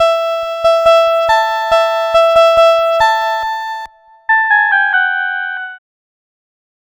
Cheese Lix Synth 140-E.wav